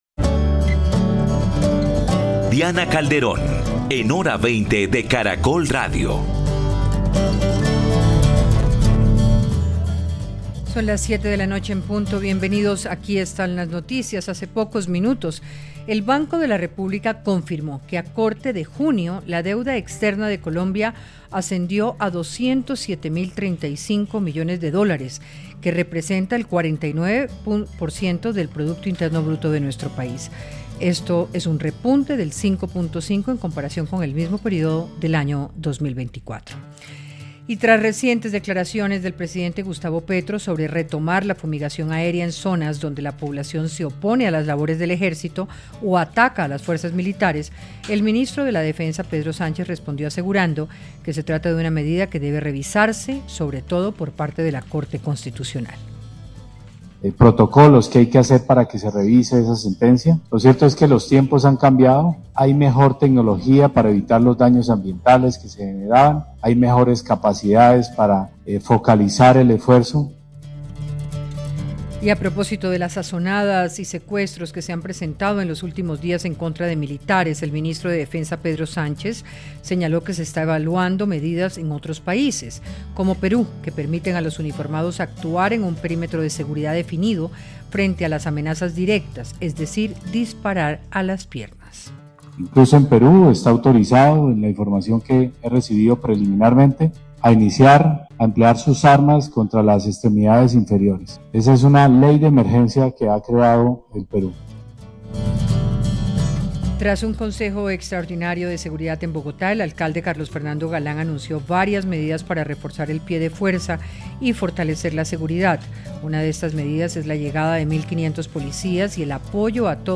Panelistas analizaron el escenario días antes de la decisión que debe tomar el gobierno de Estados Unidos en materia de lucha contra las drogas en Colombia